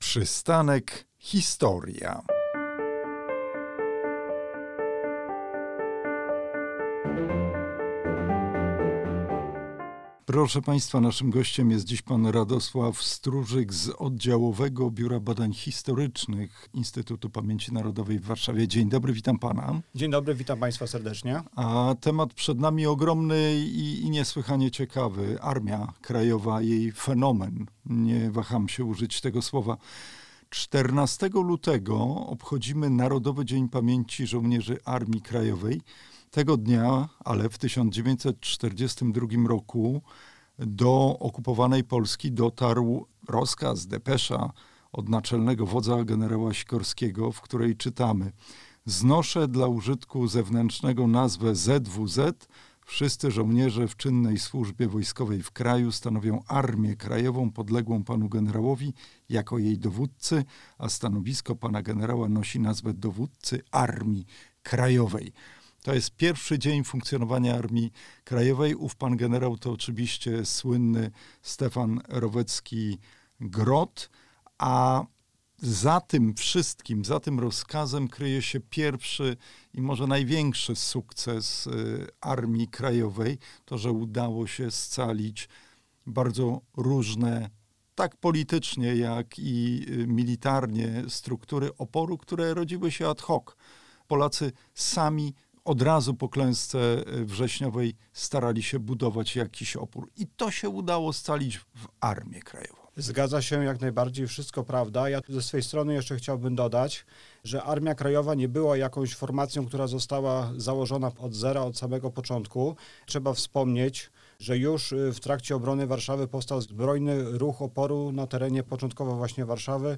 Armia Polski Podziemnej. Rozmowa z